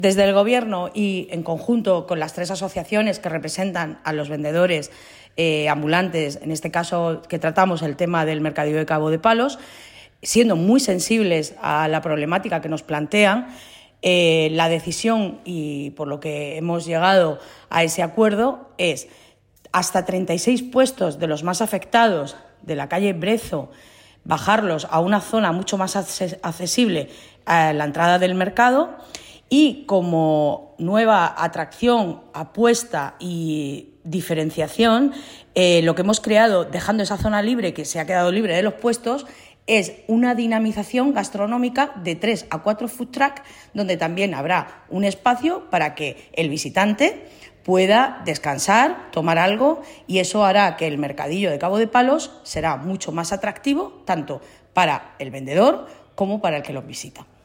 Enlace a Declaraciones de Belén Romero sobre la oferta gastronómica del mercadillo de Cabo de Palos
Así lo ha anunciado la concejal de Comercio, Hostelería y Consumo, Belén Romero, en el transcurso del pleno.